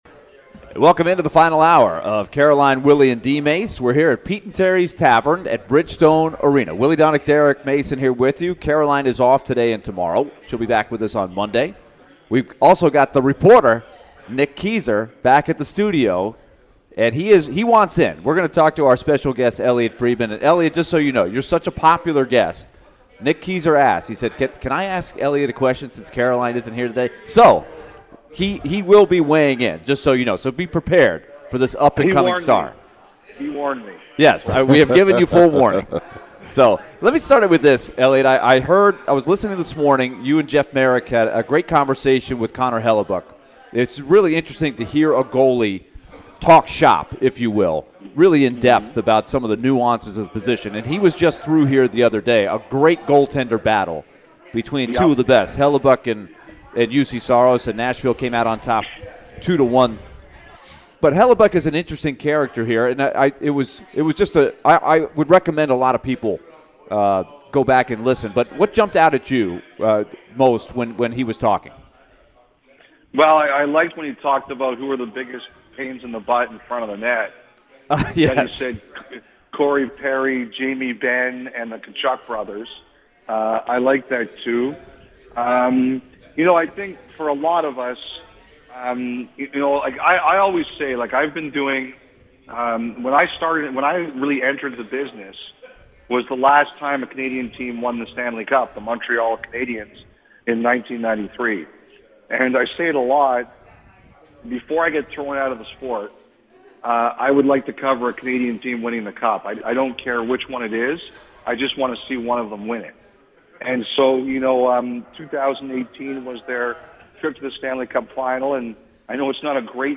Elliotte Friedman Interview (1-26-23)